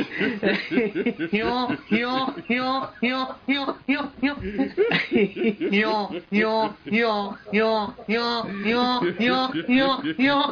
Sound Buttons: Sound Buttons View : Hiyok Laugh
laugh_mCRvXqM.mp3